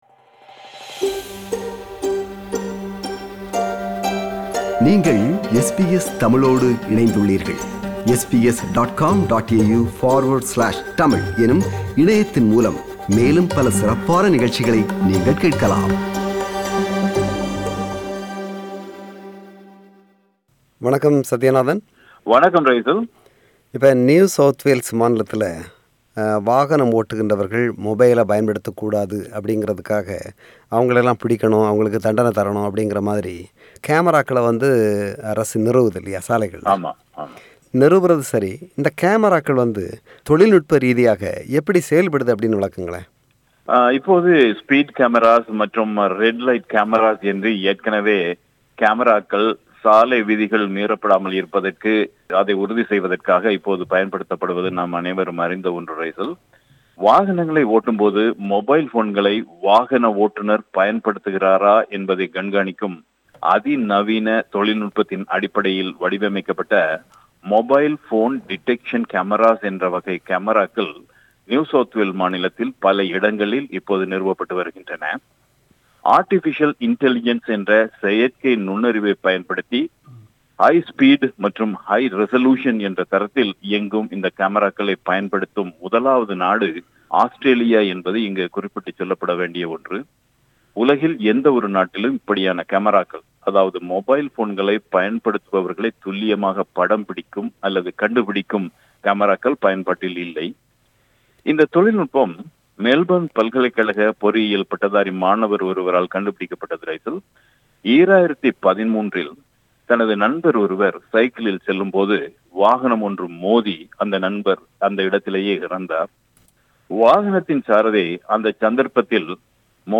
வாகனம் ஓட்டும்போது கைத்தொலைபேசிகளை பயன்படுத்தும் ஓட்டுனர்களை பிடிப்பதற்கென நியூ சவுத் வேல்ஸ் மாநிலத்தில் கமராக்கள் பொருத்தப்பட்டுள்ளமை நாமறிந்த செய்தி. இந்தக் கமராக்கள் எப்படி செயற்படுகின்றன என விளக்குகிறார் பிரபல வானொலியாளர்